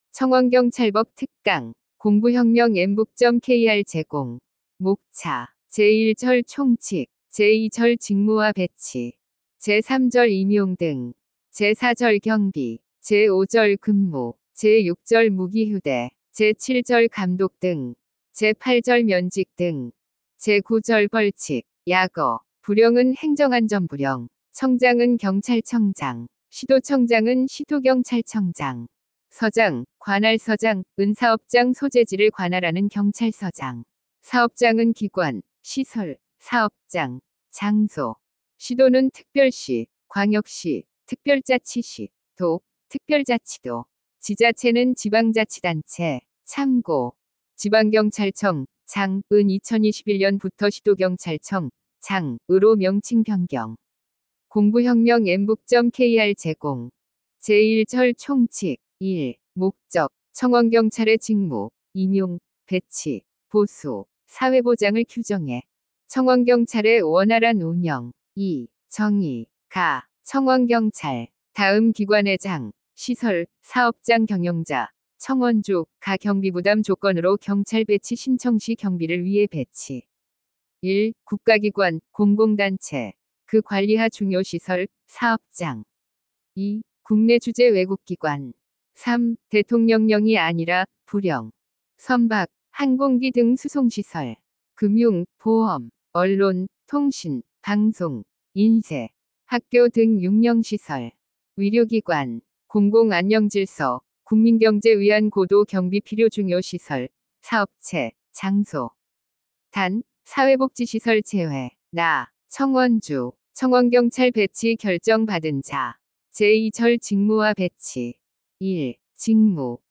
엠북학원은 우리나라 최초의 인공지능이 강의하는 사이버학원이며, 2025년 4월 28일 개원하였습니다.
청원경찰법-특강-샘플.mp3